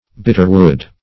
Bitterwood \Bit"ter*wood`\, n.